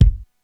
Index of /90_sSampleCDs/300 Drum Machines/Korg DSS-1/Drums01/03
Kick 01.wav